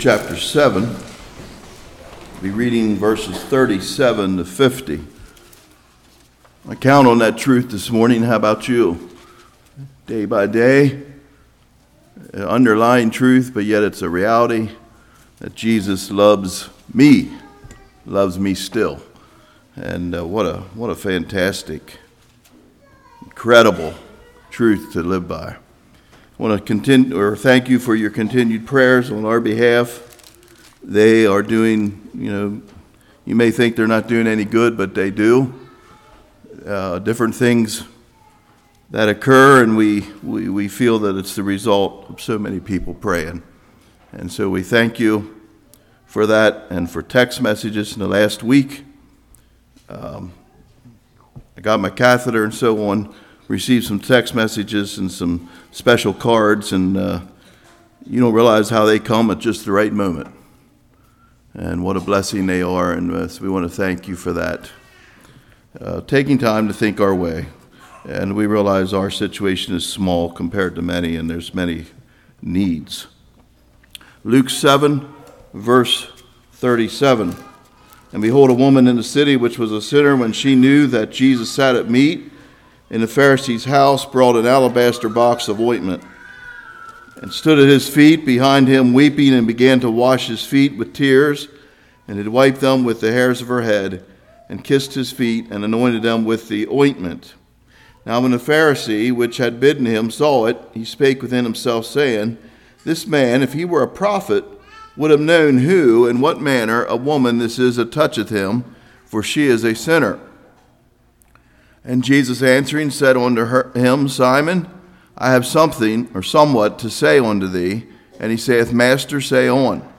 Luke 7:37-50 Service Type: Morning Love connects God to us